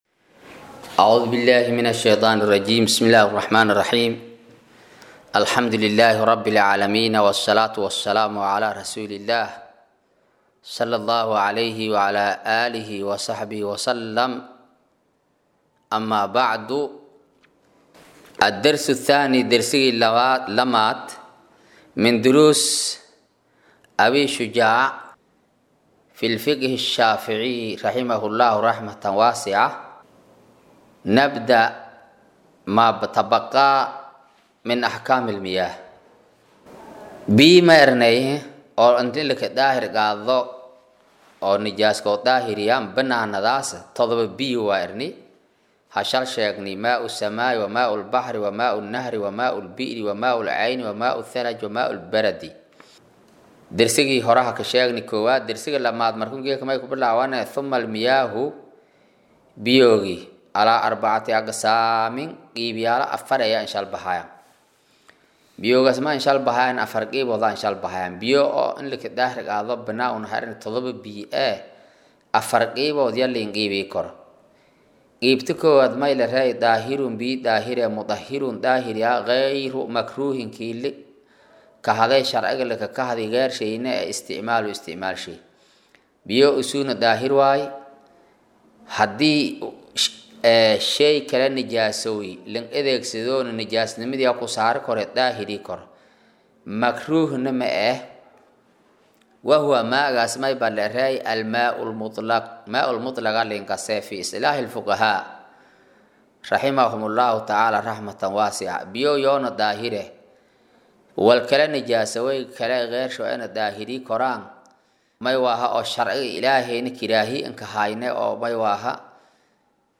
Maqal-casharka Abuu Shujaac: Darsiga2aad